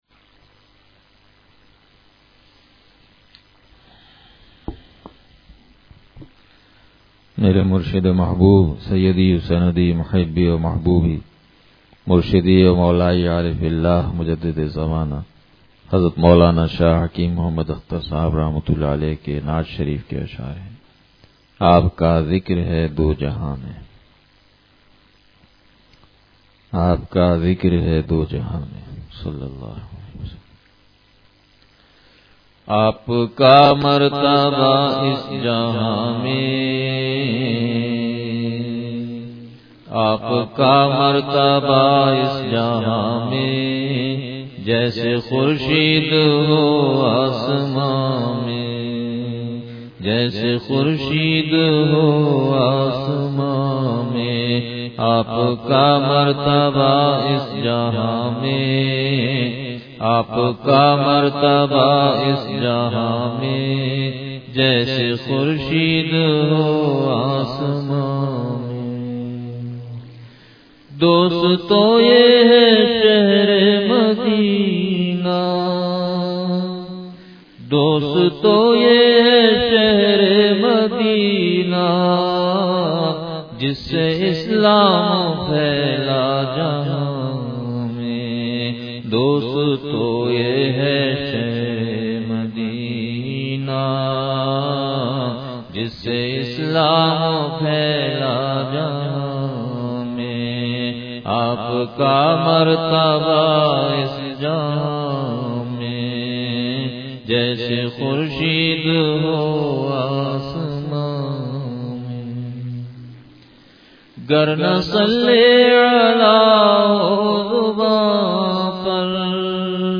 نعت شریف – آپ کا ذکر ہے دو جہاں میں – مجالس علم و حکمت